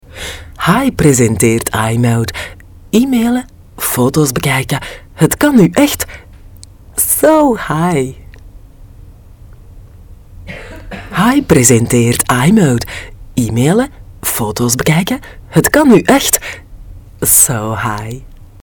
Sprecherin niederländisch aus Belgien, Weitere Sprachen: französisch und spanisch.
Sprechprobe: Sonstiges (Muttersprache):
dutch female voice over artist. experienced voice from Belgium, also French and Spanish possible. commercials, GPS,